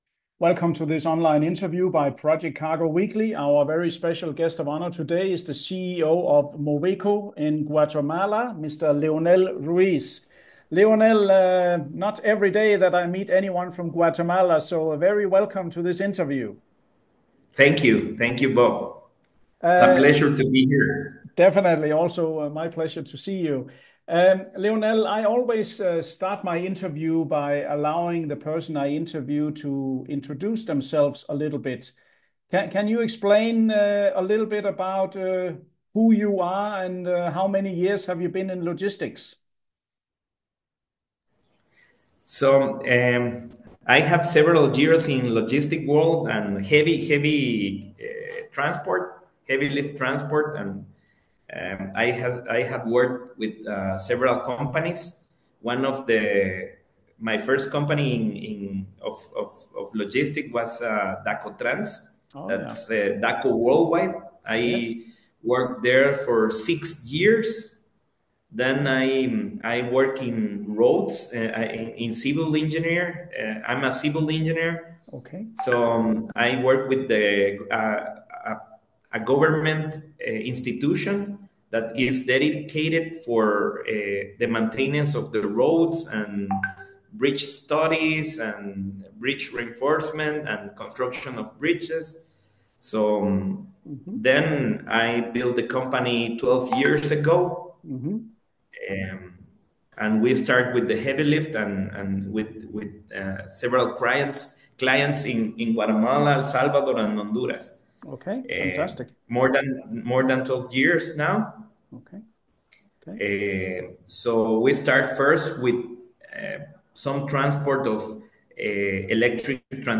Video InterviewMoveco GT